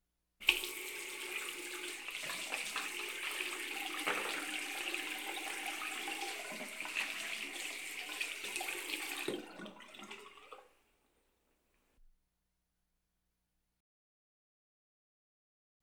Washing Hands in Sink Sound
household
Washing Hands in Sink